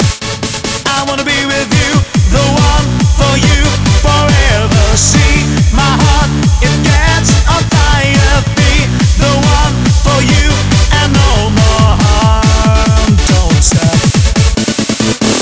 Vocal-Hook Kits